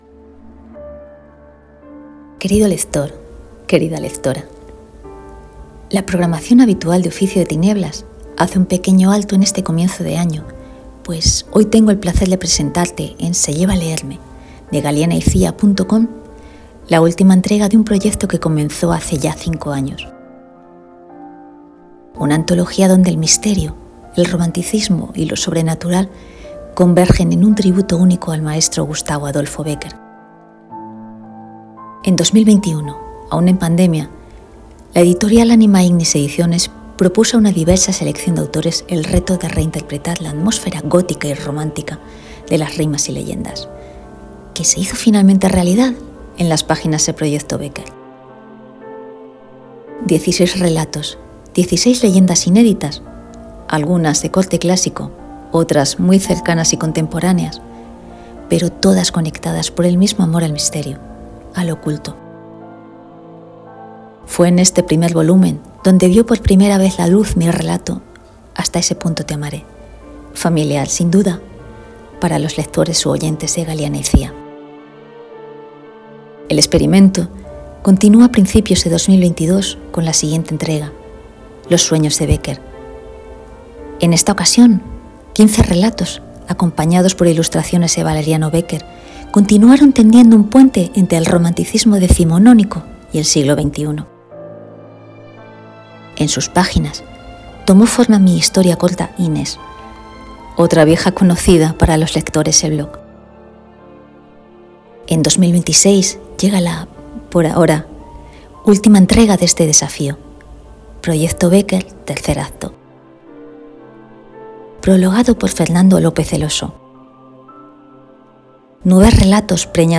Un click más y te presento esta antología de relatos con mi propia voz
proyecto-becquer-presentacion-con-musica.mp3